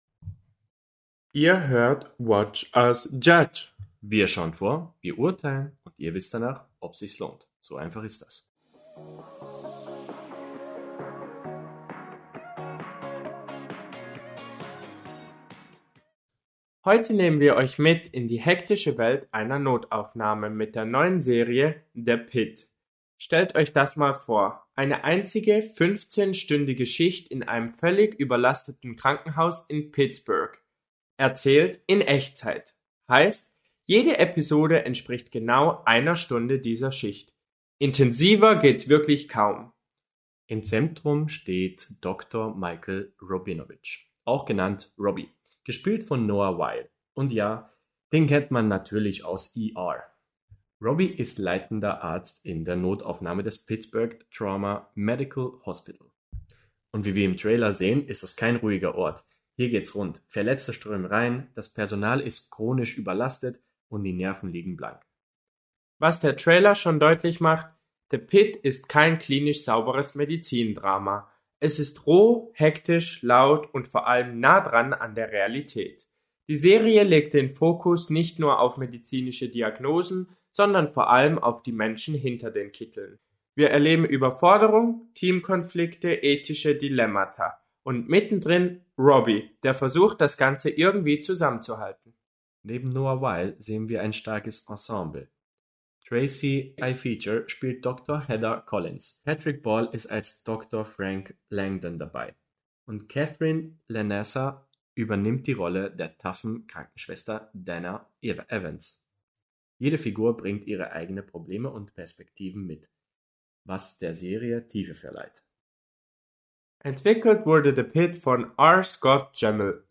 🎙 Ein Paar, eine Watchlist – und mehr Meinung als das Internet braucht.